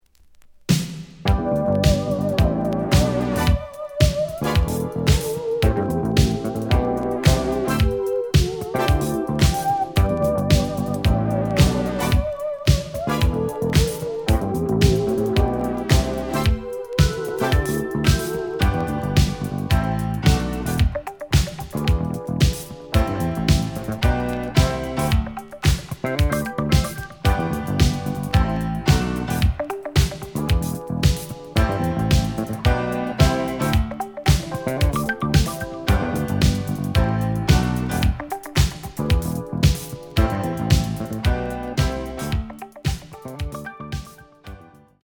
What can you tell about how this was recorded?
The audio sample is recorded from the actual item. Edge warp. But doesn't affect playing. Plays good.)